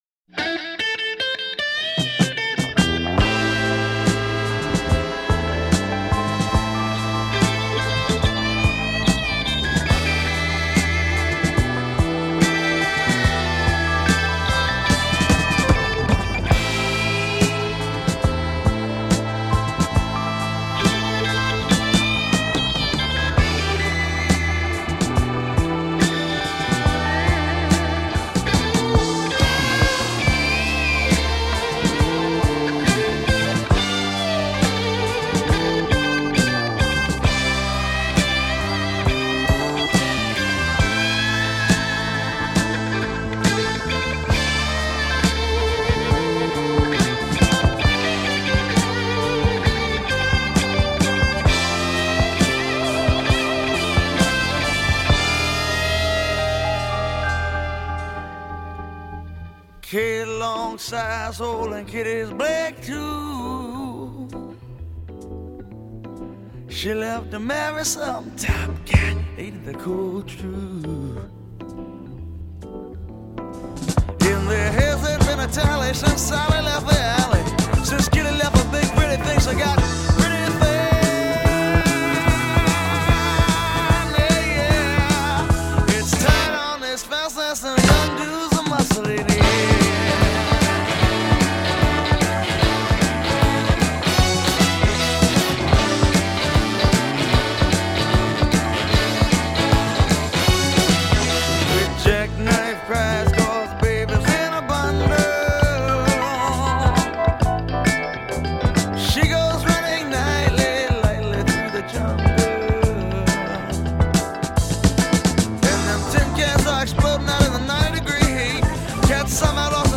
jazzy and energetic